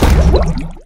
Player_Drowning.wav